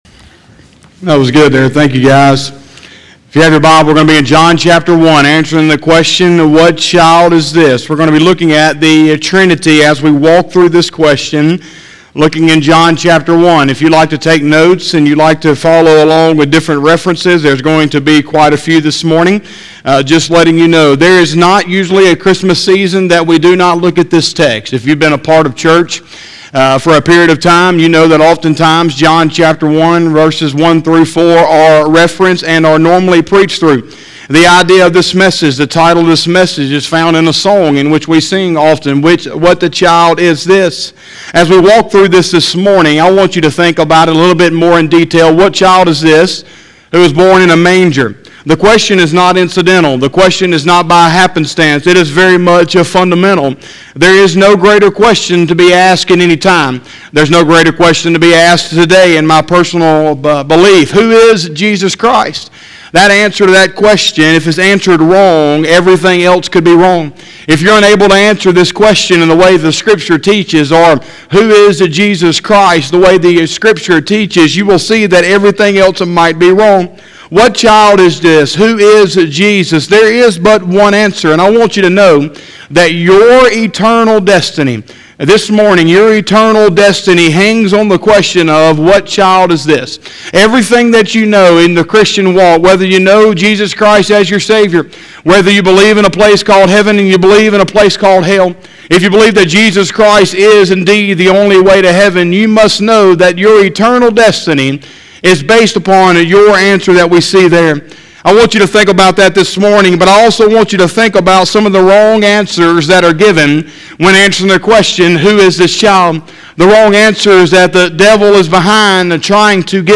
12/13/2020 – Sunday Morning Service